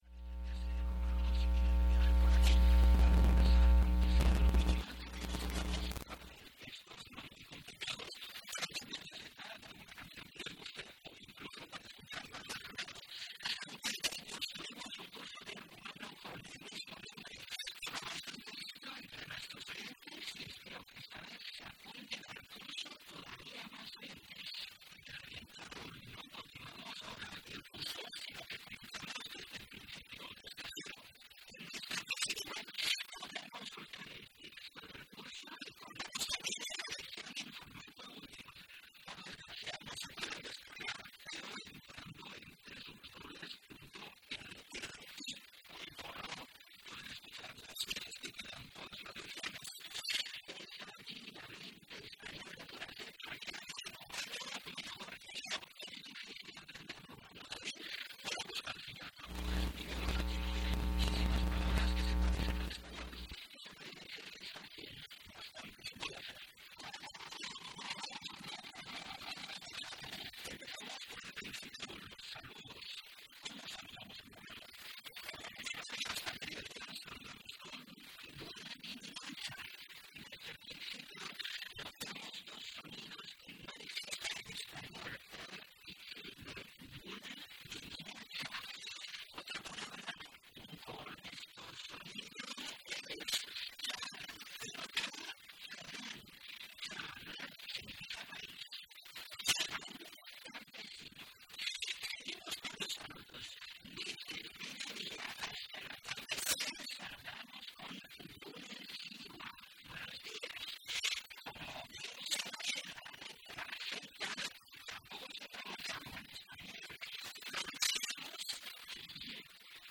La presente grabación se realizó en un radio de onda corta de la marca Keenwood modelo R-600 por la frecuencia de 15,300 khz el día 1 de abril de 2014 a las 15:20 horas tiempo local y las 21:20 horas Tiempo Universal Coordinado.